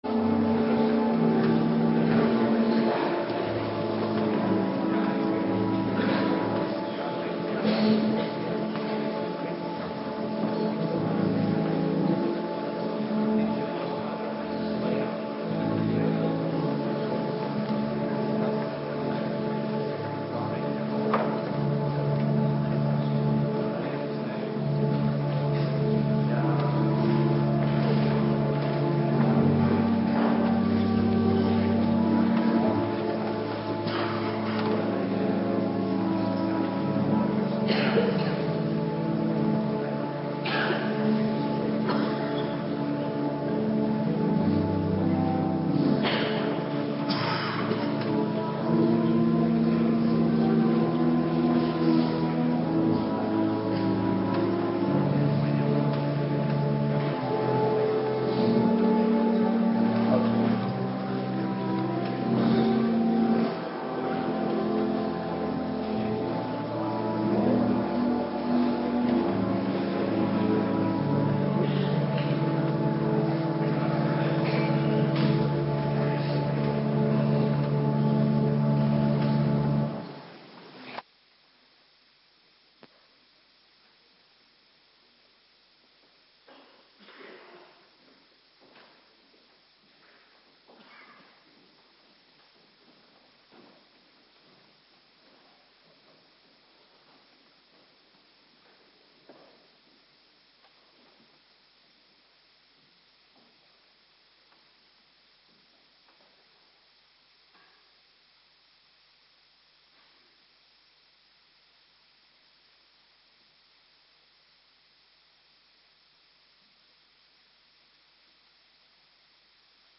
Biddag avonddienst